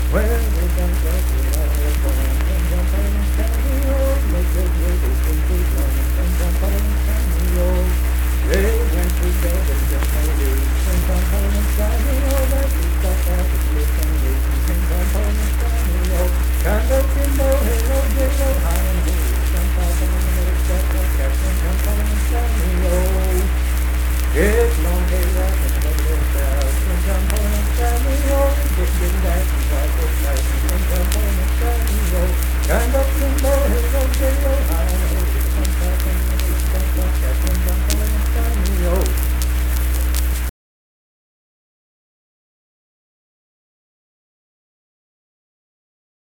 Unaccompanied vocal music
Performed in Kanawha Head, Upshur County, WV.
Minstrel, Blackface, and African-American Songs
Voice (sung)